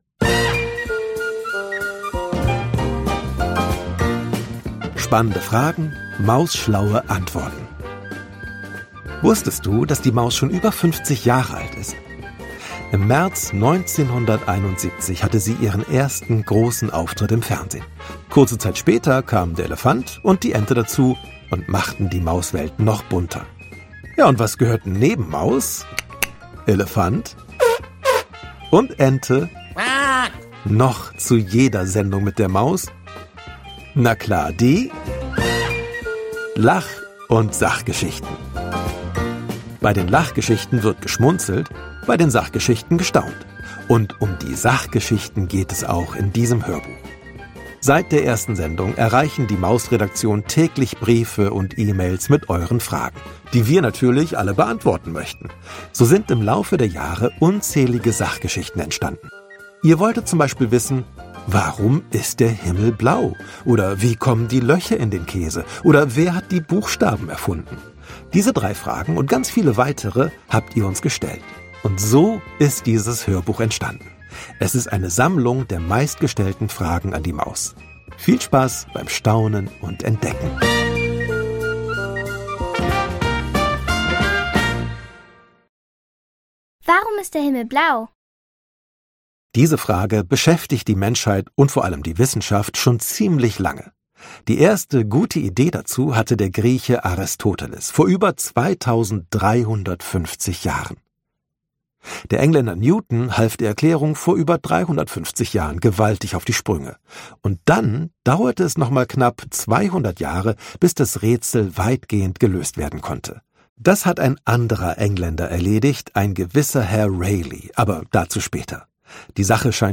Ralph Caspers (Sprecher)